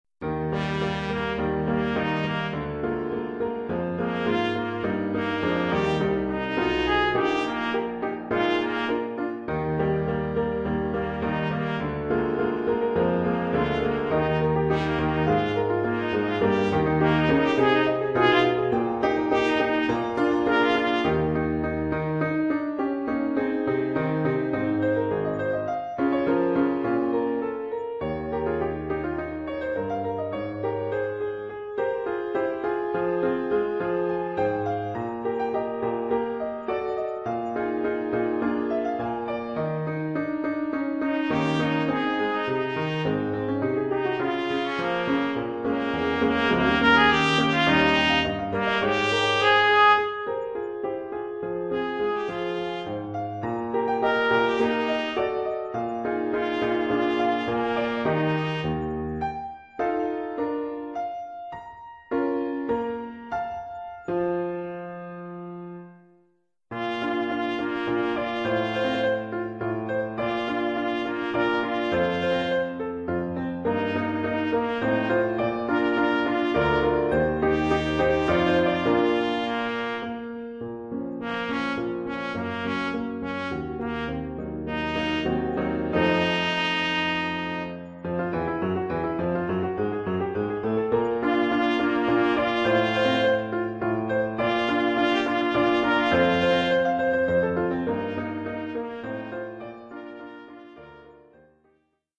Trompette de cavalerie Mib
Oeuvre pour trompette mib et piano.